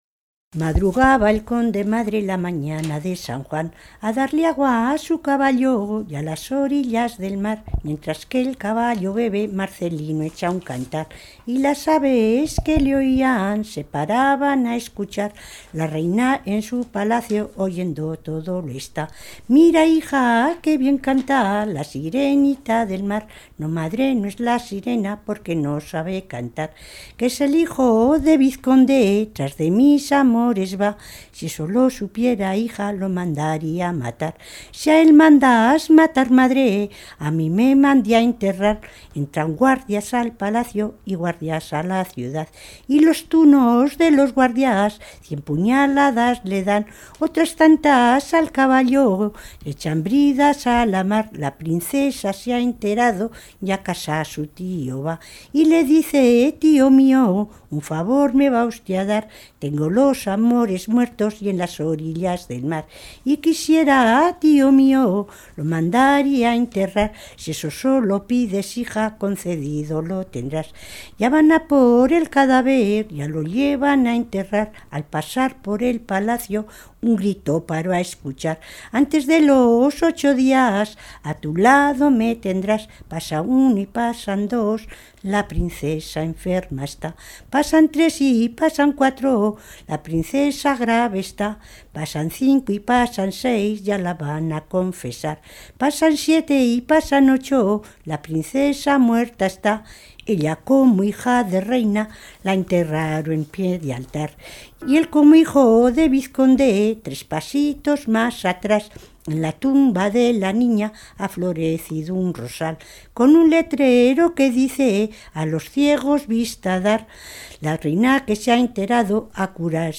Clasificación: Romancero
Localidad: Ribafrecha
Lugar y fecha de recogida: Logroño, 26 de abril de 2004
Hemos identificado una similitud de melodía y de letra con otras versiones cercanas geográficamente, en Lagunilla de Jubera y en Avellaneda de Cameros que está en el mismo valle del río Leza.